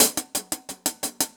Index of /musicradar/ultimate-hihat-samples/175bpm
UHH_AcoustiHatC_175-03.wav